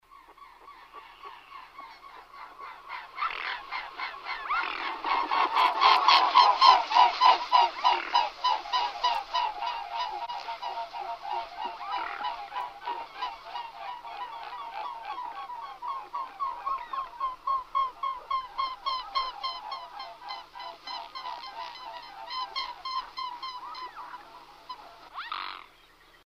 Звуки лебедей
Вы можете слушать или скачать их голоса, шум крыльев и плеск воды в высоком качестве.